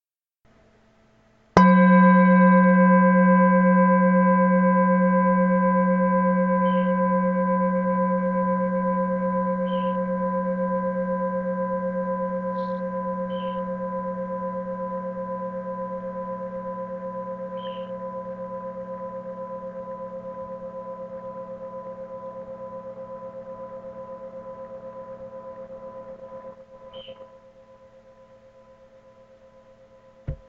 Planeten-Klangschale matt 1026 gr, 19 cm Ø, "Mars"
Tibetische Klangschale,
hergestellt in Nepal, 7 Metalle, matt,
nach alter Tradition handgetrieben.